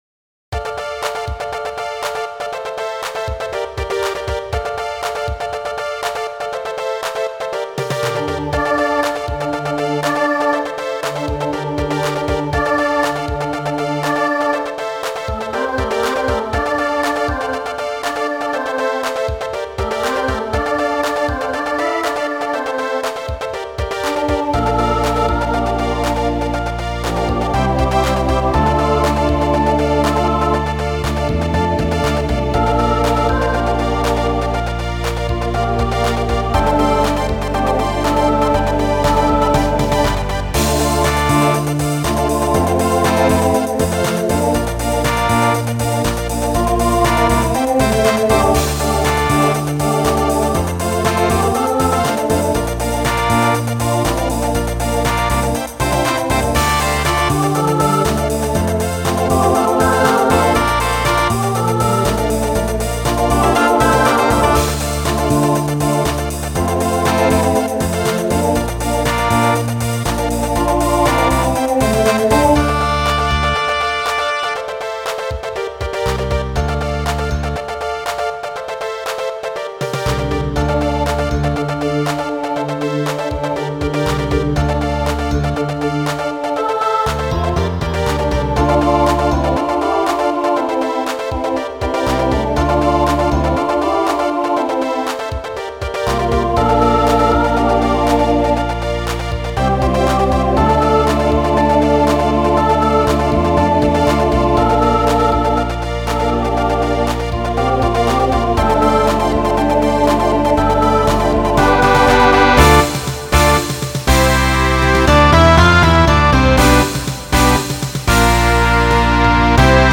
Voicing SATB Instrumental combo
Pop/Dance , Rock Decade 2010s Show Function Mid-tempo